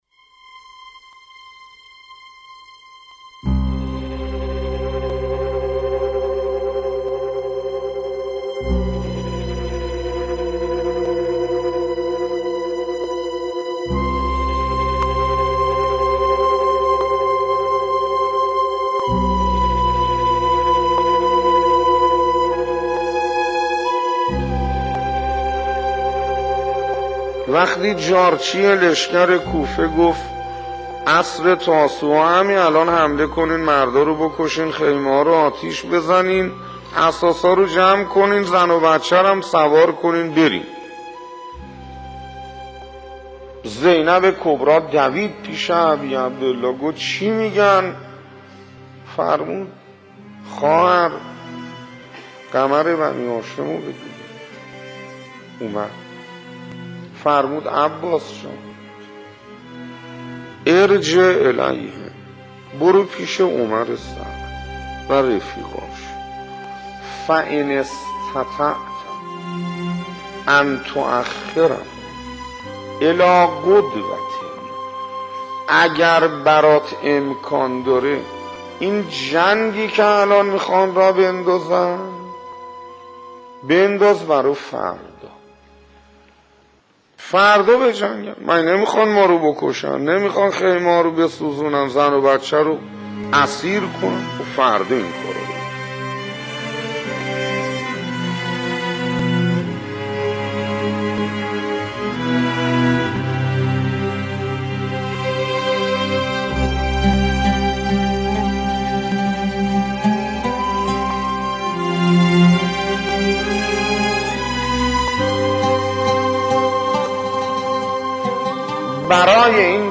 نواهنگ نماز از حجت الاسلام انصاریان